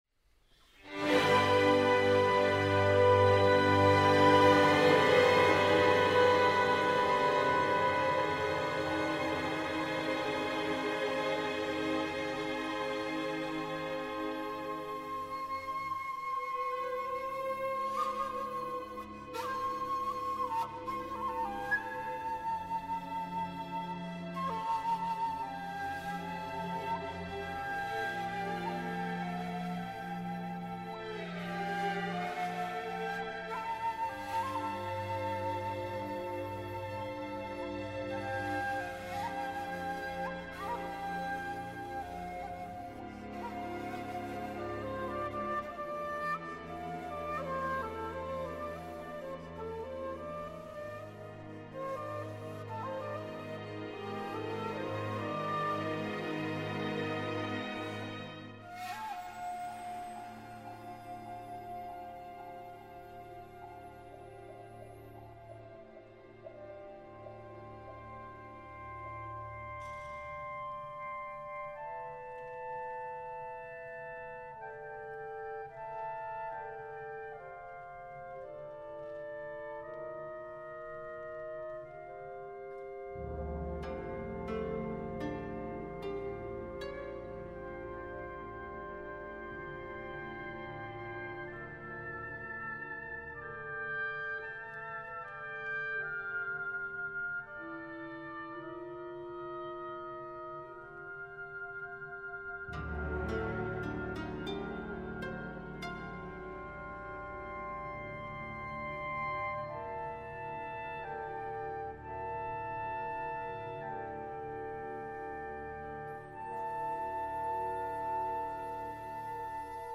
shakuhachi, 21-string koto & orchestra